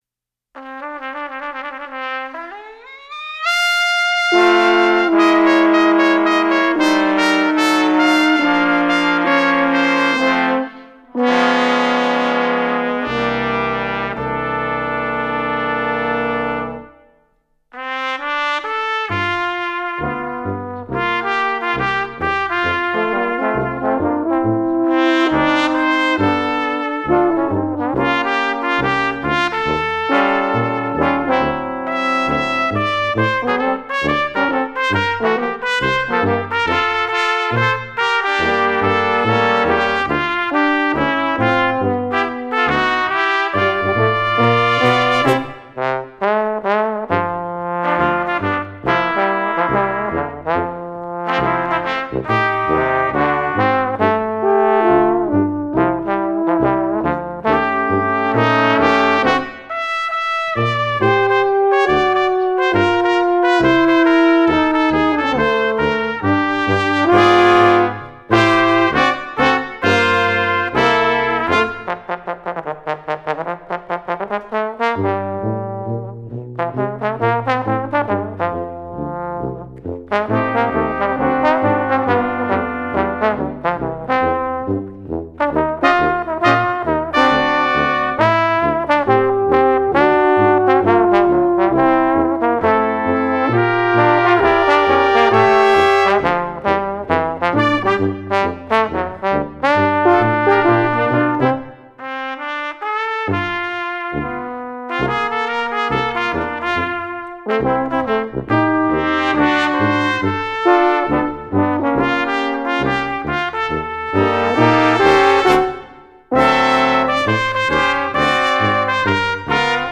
Besetzung: Ensemblemusik für 5 Blechbläser